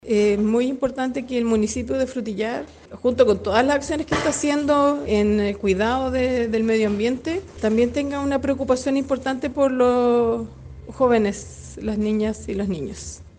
En Tanto, la Seremi de Medio Ambiente, Carola Iturriaga, valoró la gestión ambiental de la administración municipal del Alcalde Huenuqueo.
SEMINARIO-MEDIO-AMBIENTE-FRUTILLAR-SEREMI.mp3